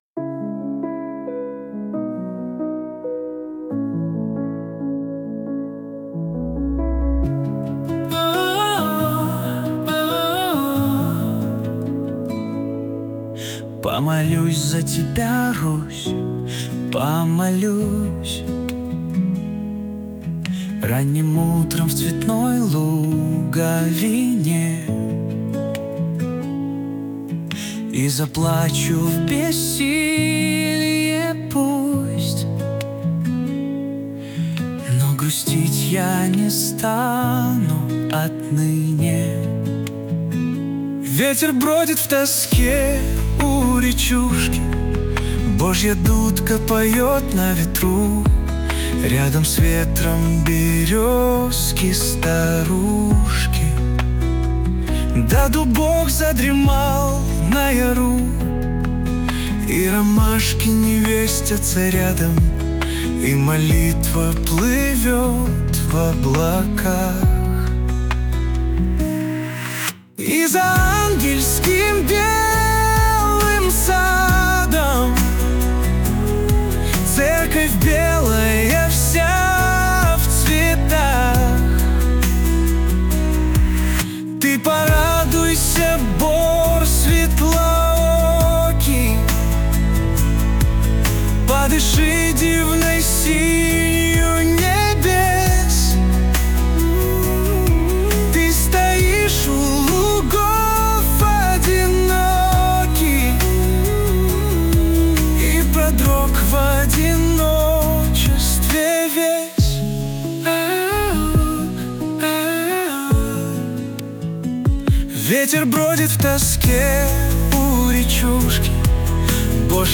песня сгенерирована автором в нейросети